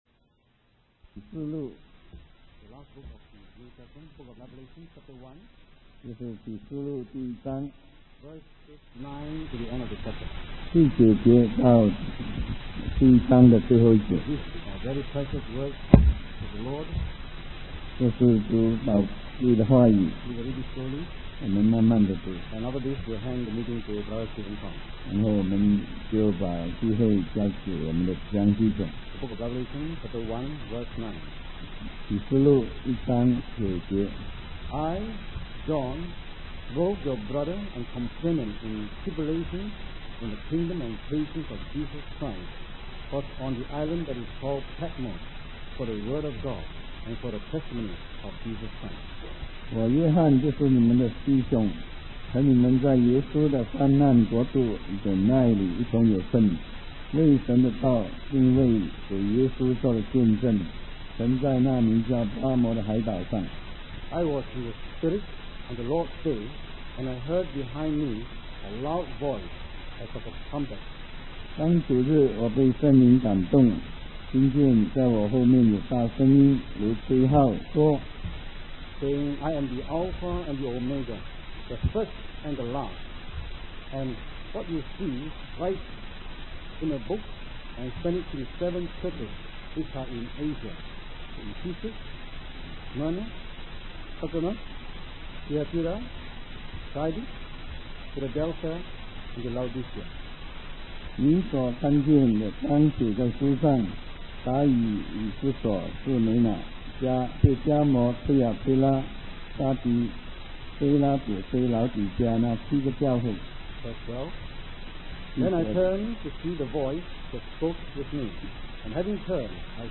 In this sermon, the speaker discusses the vision given to the Apostle John while he was exiled on the island of Patmos. John's heart was still with the people of the seven cities in Asia, and he wondered who would take care of them.